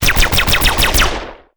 machine1.wav